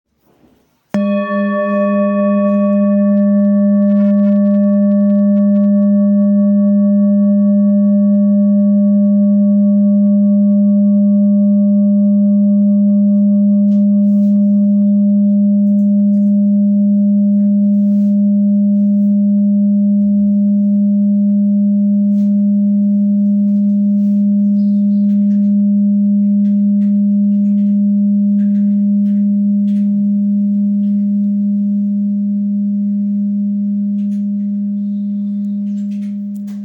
Full Moon Bowl, Buddhist Hand Beaten, Moon Carved, Antique Finishing
Material Seven Bronze Metal
This is a Himalayas handmade full moon singing bowl. The full moon bowl is used in meditation for healing and relaxation sound therapy.